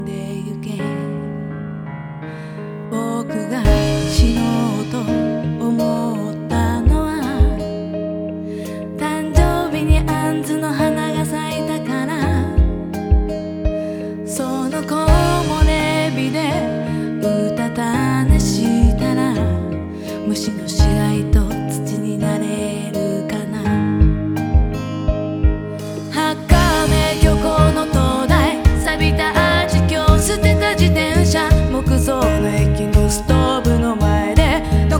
Жанр: Поп / J-pop